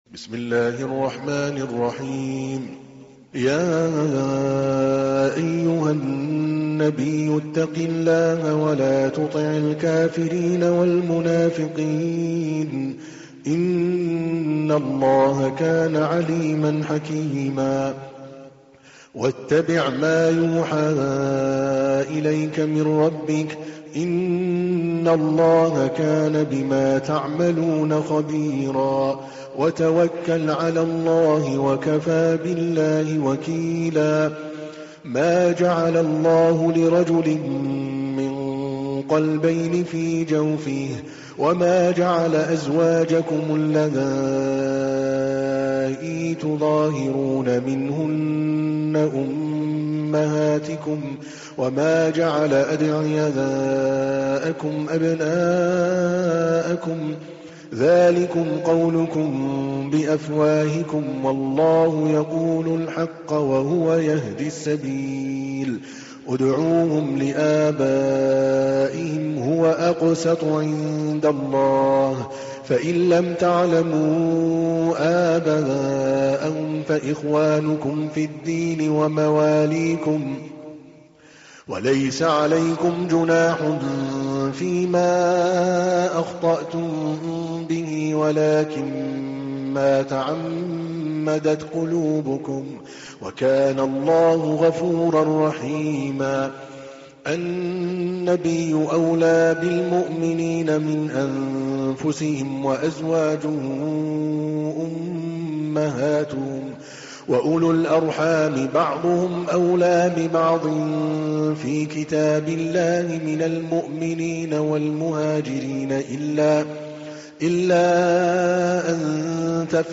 تحميل : 33. سورة الأحزاب / القارئ عادل الكلباني / القرآن الكريم / موقع يا حسين